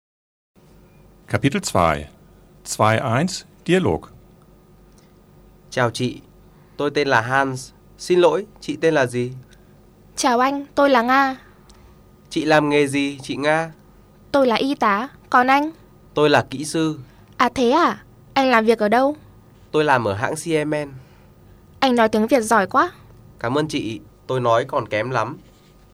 Die Tonaufnahmen wurden von sechs Muttersprachlern, die alle Standardvietnamesisch sprechen, im modernen Tonstudio der Fakultät für Medien der Universität für Sozial- und Geisteswissenschaften Hanoi aufgenommen.